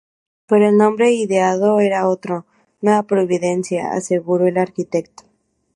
ar‧qui‧tec‧to
/aɾkiˈteɡto/